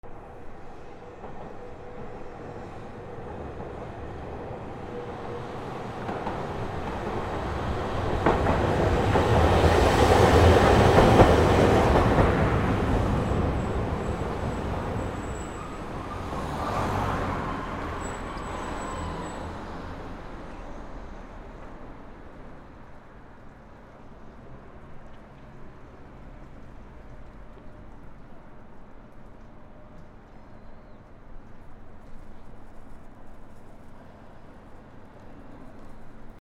路面電車通過